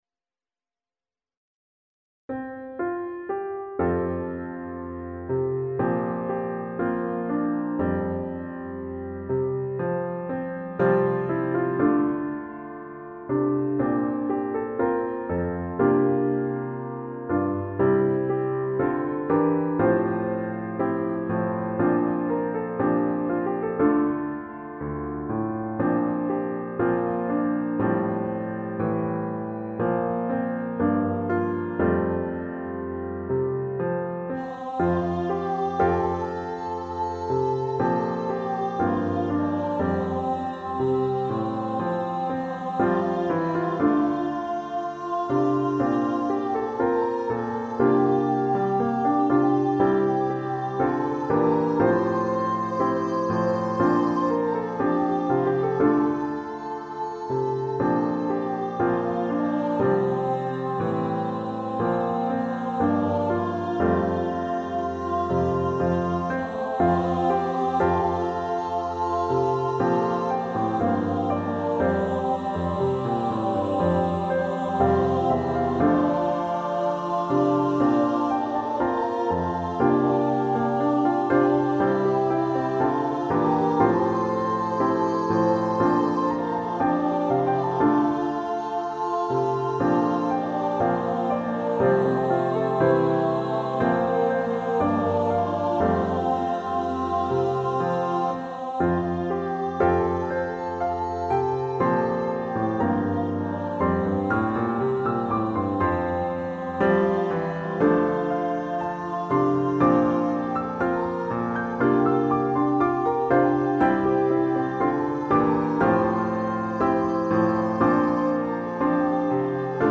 Piano and Vocal PDF